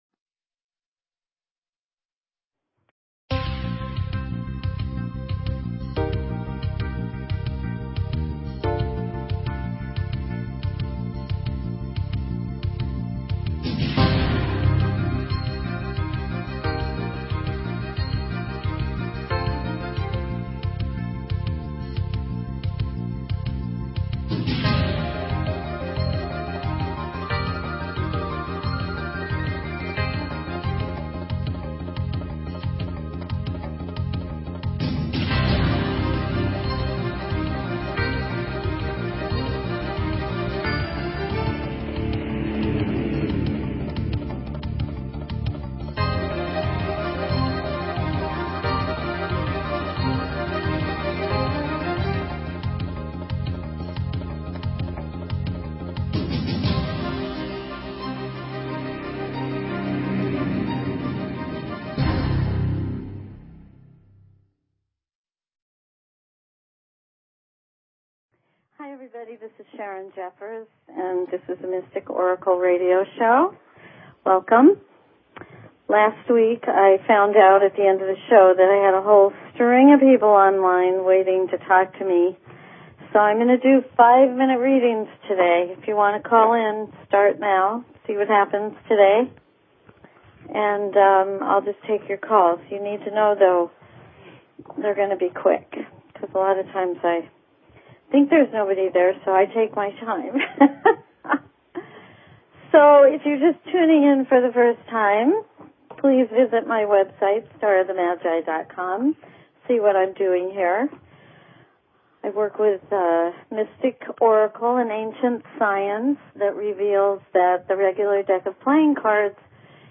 Talk Show Episode, Audio Podcast, The_Mystic_Oracle and Courtesy of BBS Radio on , show guests , about , categorized as
Discover the secrets hidden in your birthday, your relationship connections, and your life path. Open lines for calls.